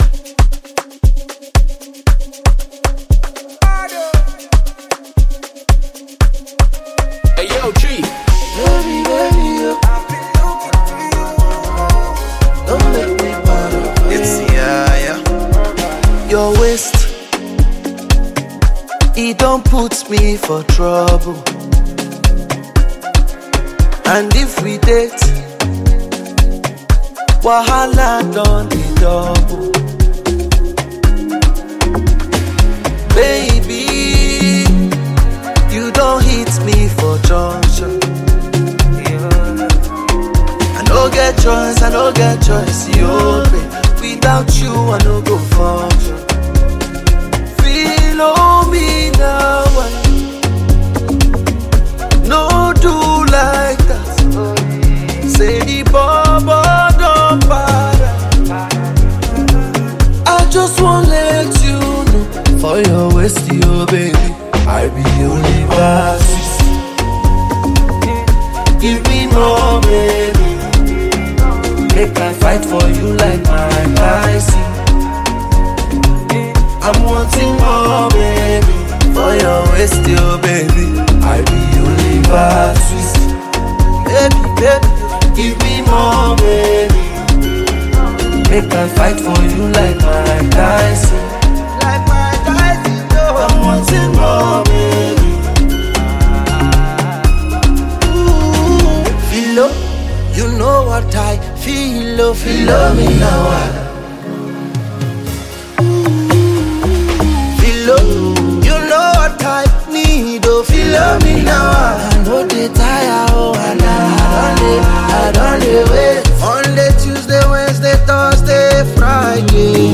remix version